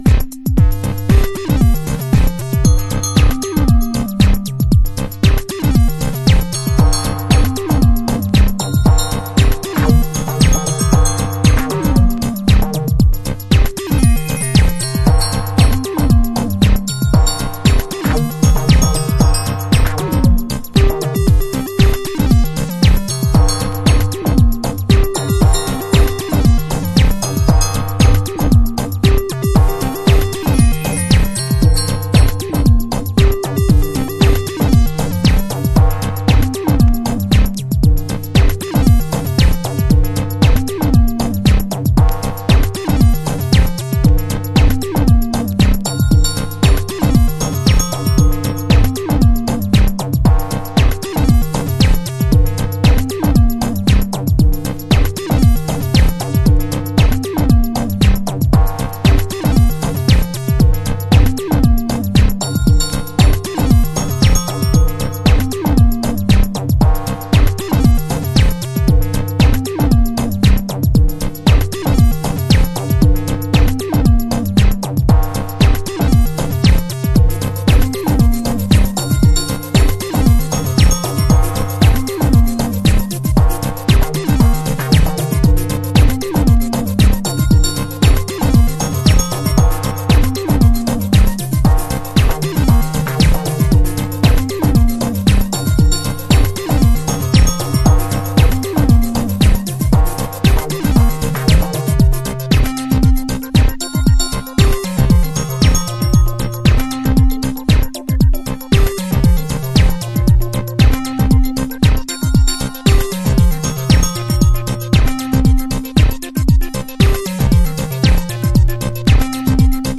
シンプルな素材でひねてるレトロフューチャーなジャッキン/エレクトロトラック。